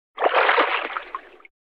splash.mp3